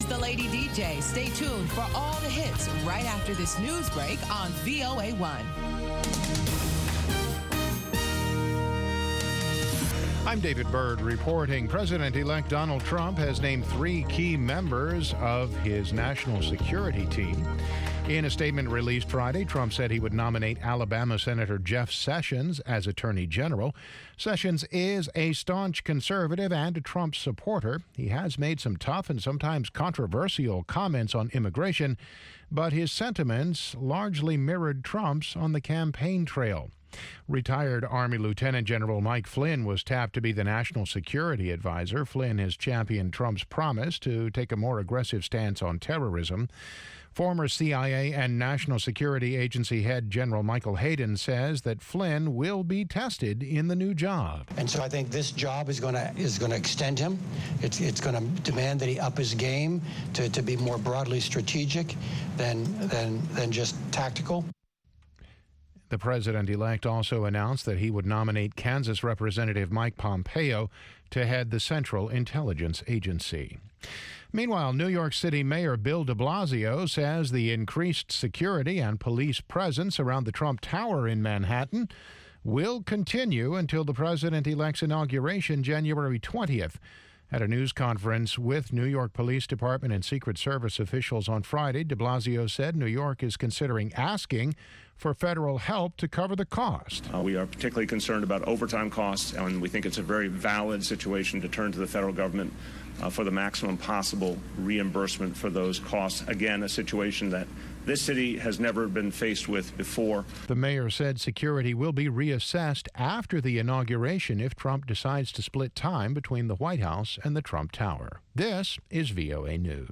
Una discusión de 30 minutos sobre los temas noticiosos de la semana con diplomáticos, funcionarios de gobiernos y expertos.